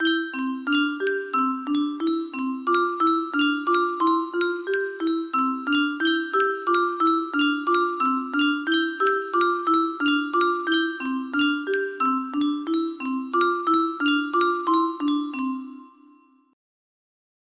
Summ summ summ music box melody